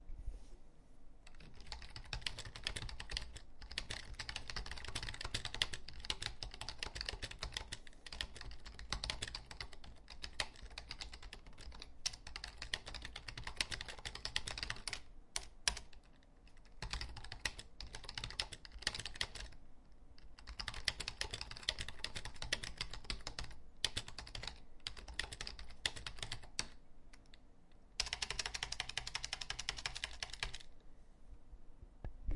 描述：办公室的气氛。电话响铃用笔点击。
标签： 打字 办公 电脑 手机
声道立体声